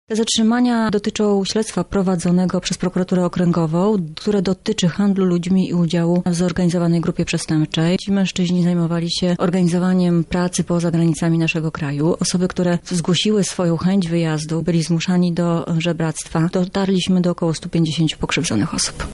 O charakterze stawianych im zarzutów mówi komisarz